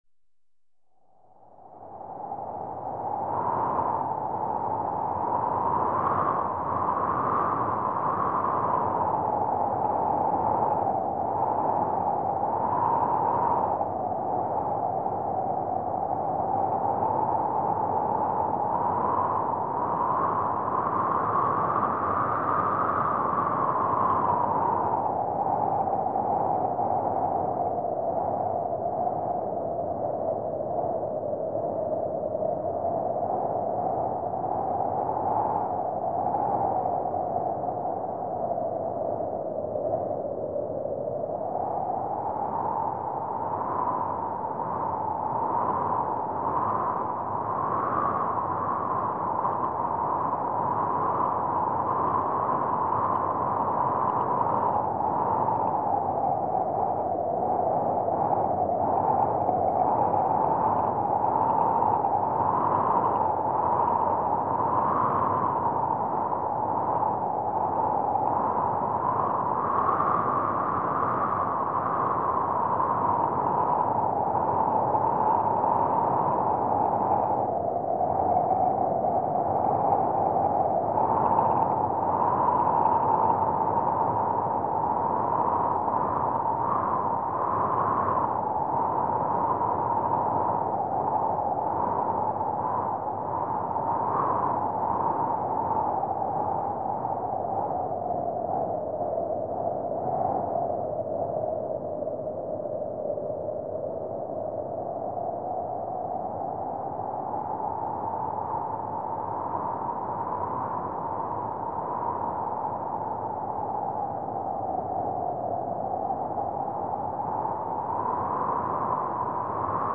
3D spatial surround sound "Wind noise"
3D Spatial Sounds